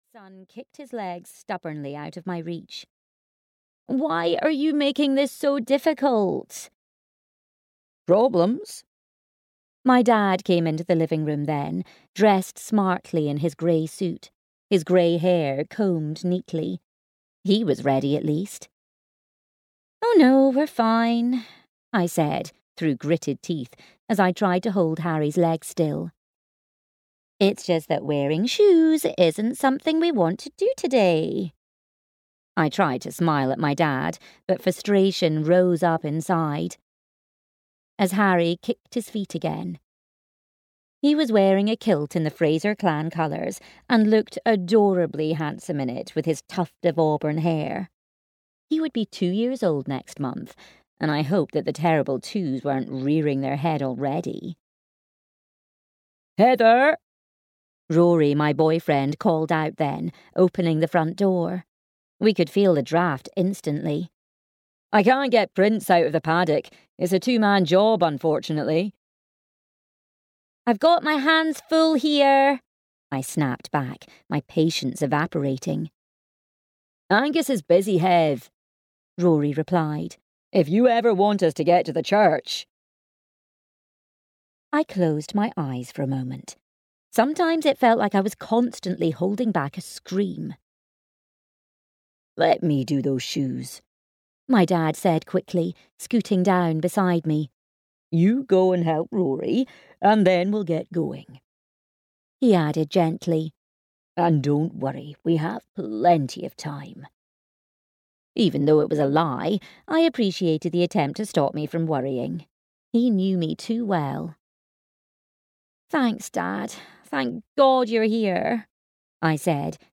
Hopeful Hearts at Glendale Hall (EN) audiokniha
Ukázka z knihy